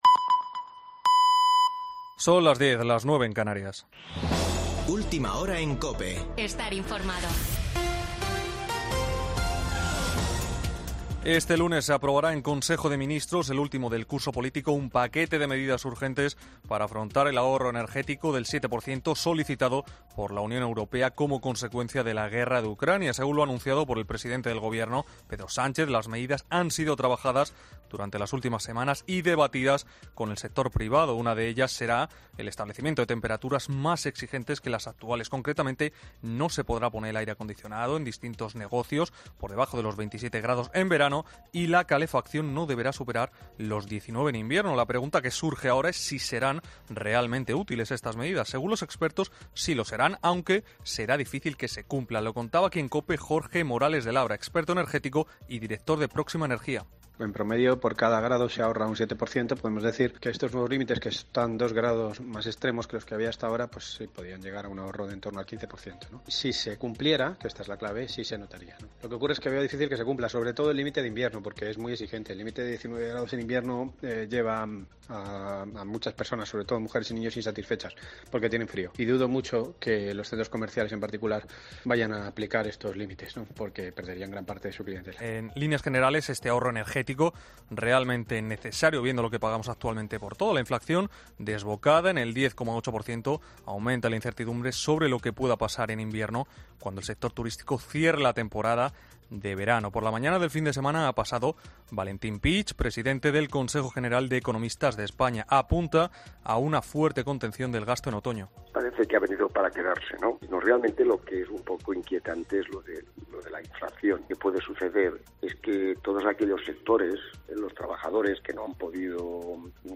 Boletín de noticias de COPE del 31 de julio de 2022 a las 22.00 horas